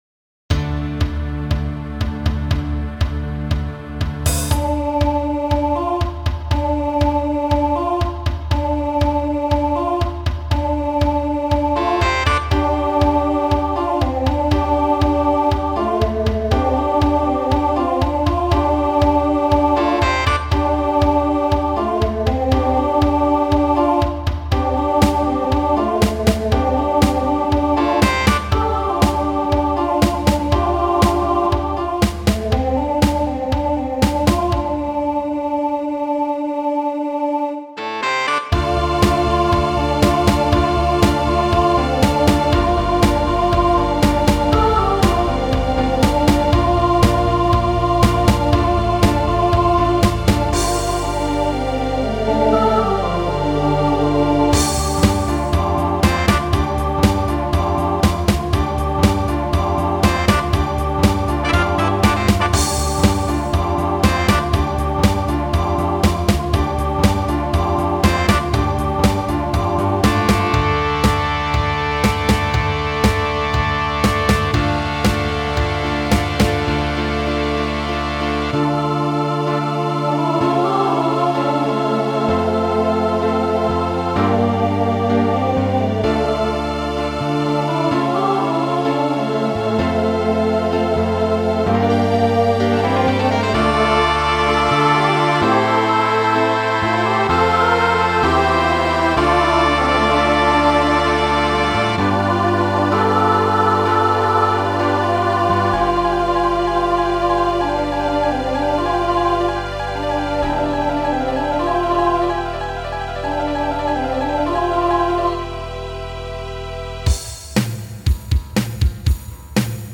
SSA/TTB
Instrumental combo
Pop/Dance , Rock